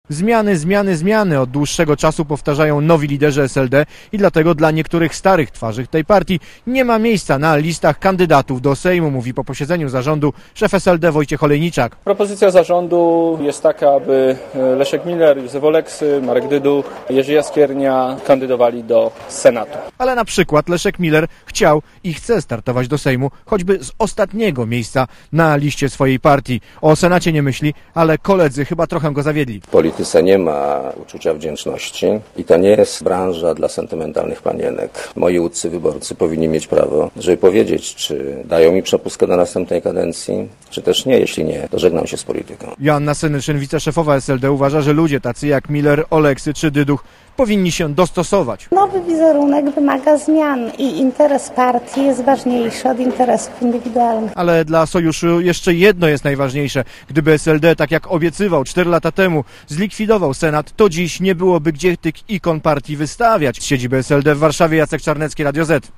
Źródło zdjęć: © RadioZet 18.07.2005 17:29 ZAPISZ UDOSTĘPNIJ SKOMENTUJ Relacja reportera Radia ZET